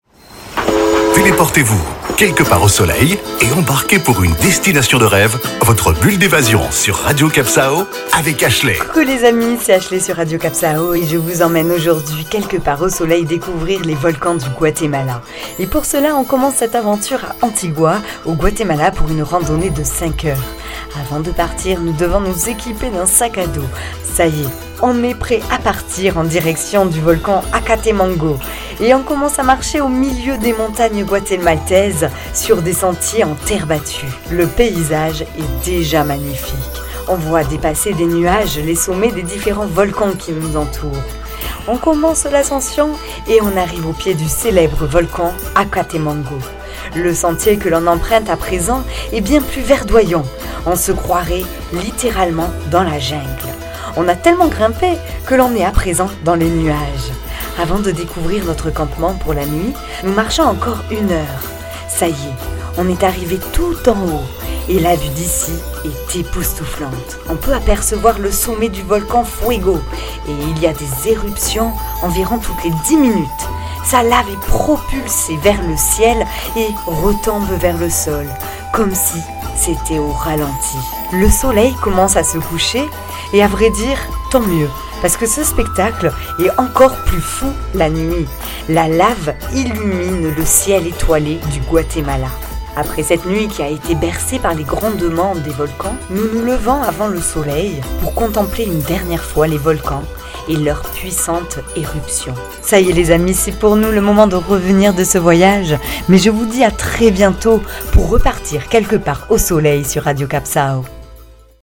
Carte postale sonore : montez au sommet des volcans majestueux du pays et réchauffez-vous au plus prêt de leurs cratères ...